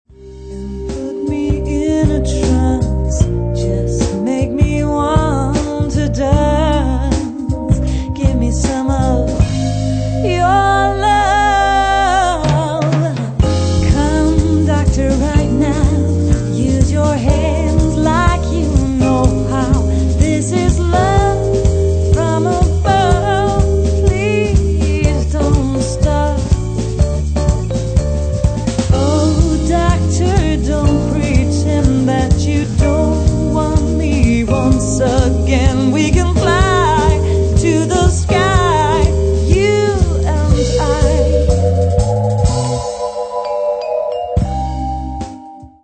ist ein zeitloser Clubsound